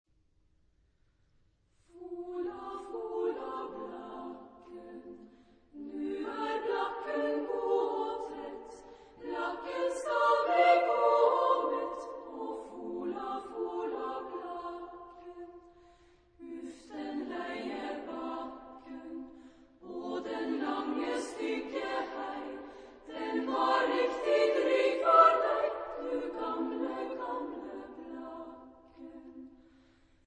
Genre-Style-Form: Popular ; Lied
Mood of the piece: allegretto
Type of Choir: SSA  (3 women voices )
Tonality: F major